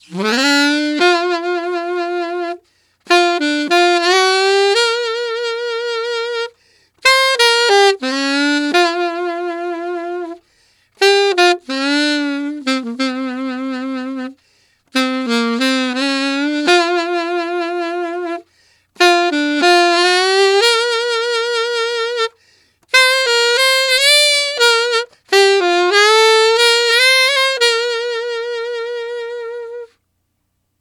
Alto One Shot in Bb 01.wav